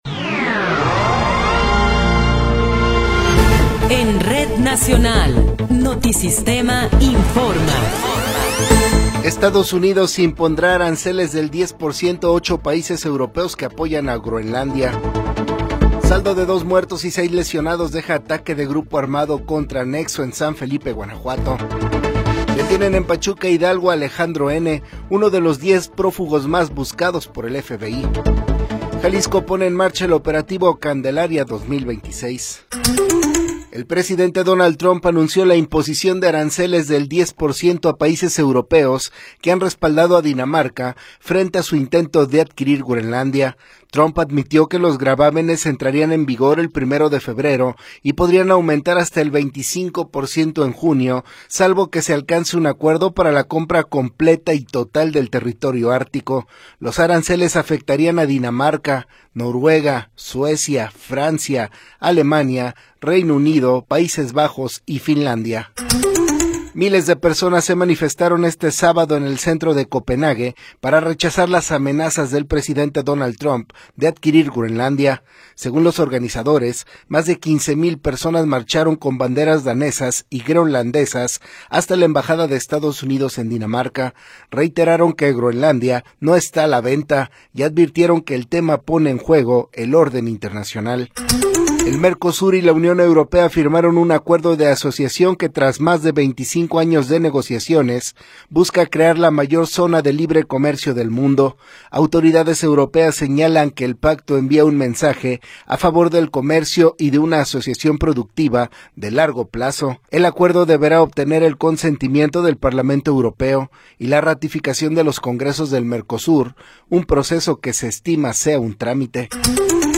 Noticiero 20 hrs. – 17 de Enero de 2026